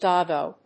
音節dog・go 発音記号・読み方
/dˈɔːgoʊ(米国英語), dˈɔgəʊ(英国英語)/